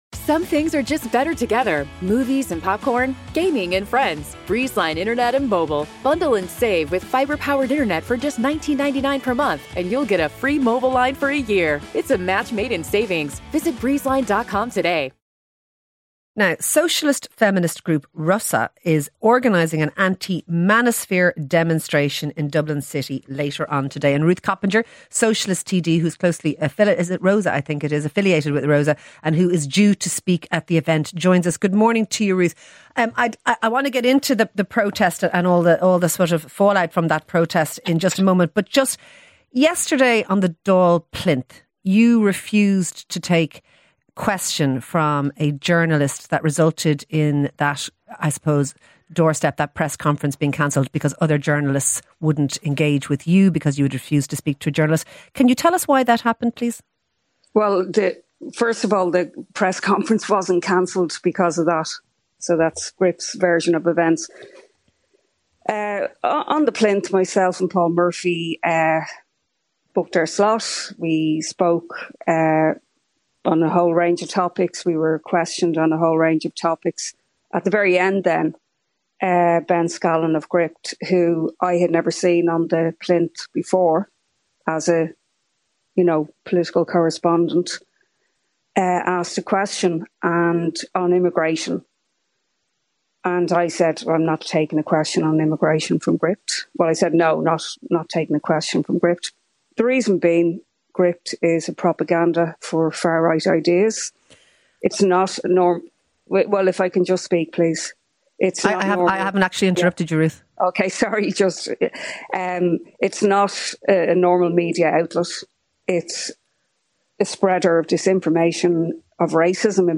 ruth_coppinger_interview_final_8ffd22d4_normal.mp3